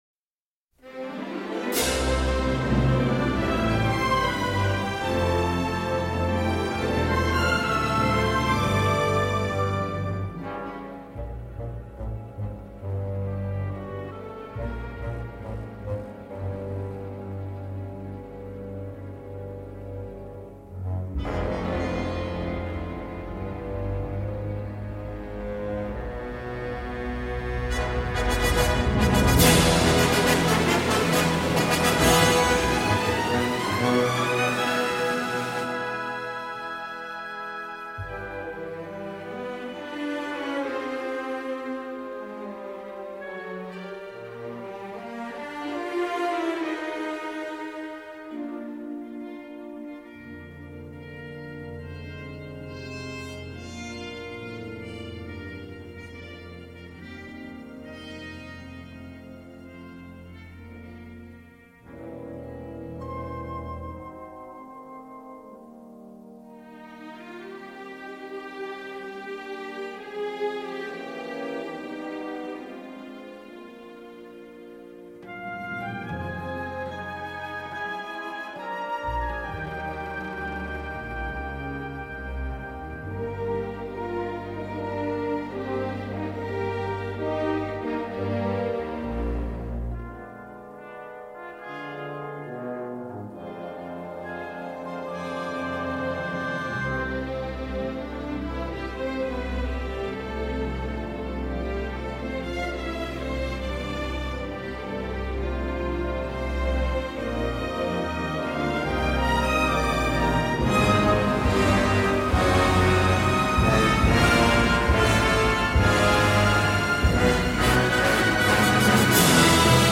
se la joue premier degré avec un score flamboyant.